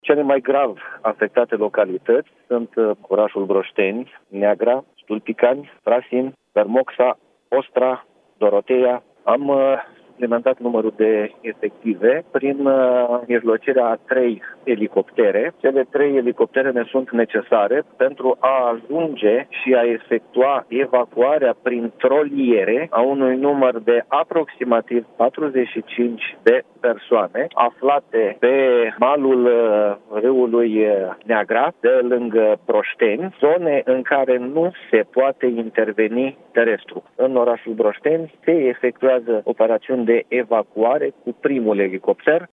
Prefectul județului Suceava, Traian Andronachi: „Am suplimentat numărul de efective prin mijlocirea a trei elicoptere”